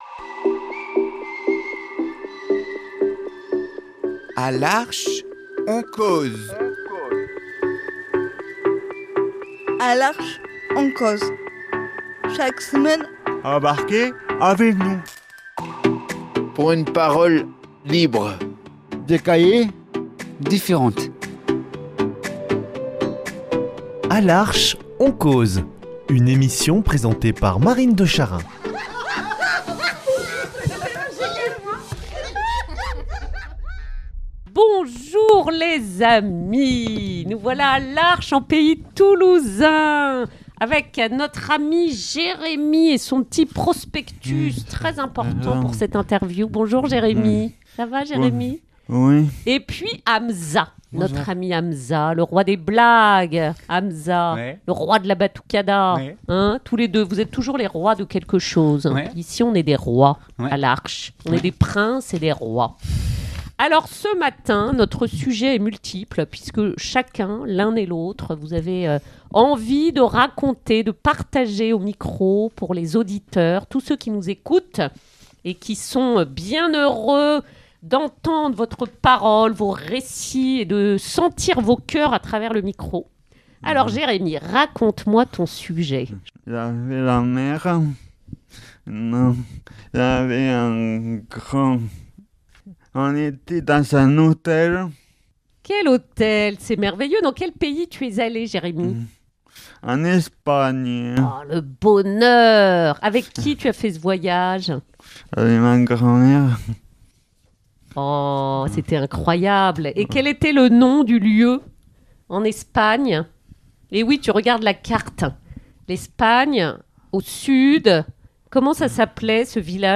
La causette est joyeuse, drôle, spontanée, libre, et c’est le bonheur !